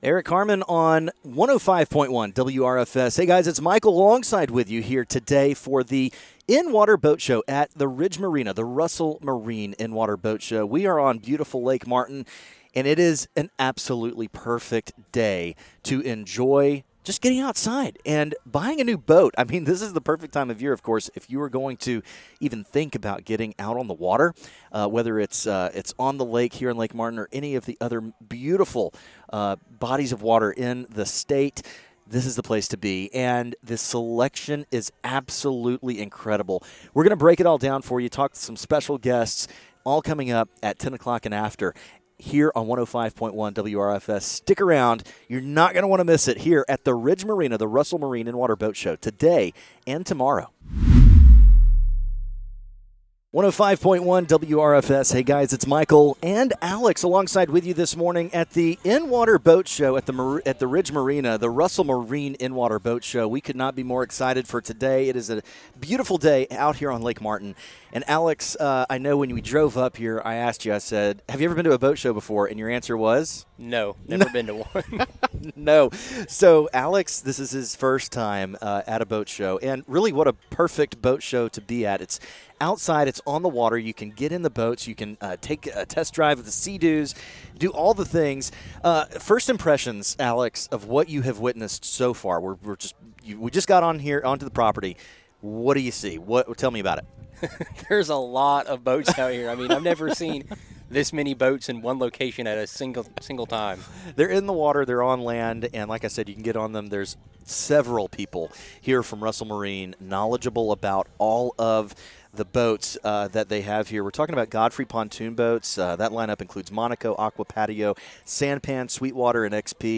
Live from the 2025 Russell Marine In-Water Boat Show